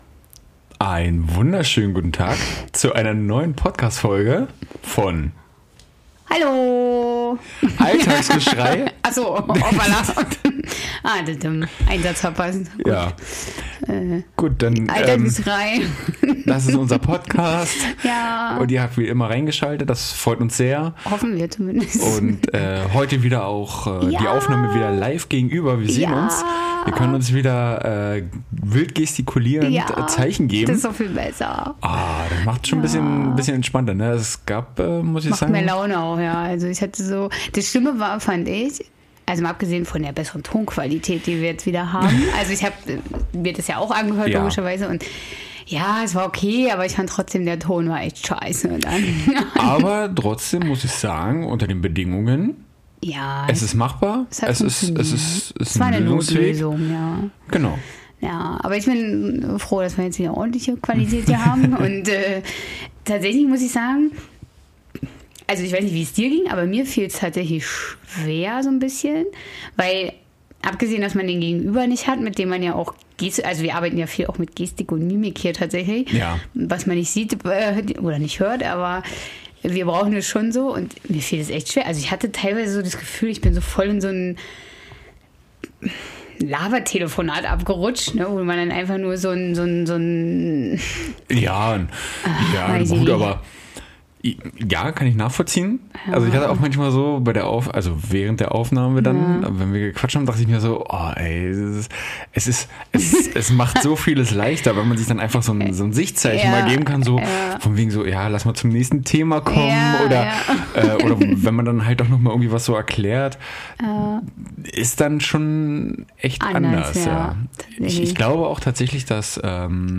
Wie der Titel es bereits verrät, sind wir endlich wieder vereint und können euch in der gewohnten Qualität was auf die Ohren geben.